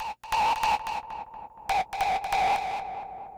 synthFX02.wav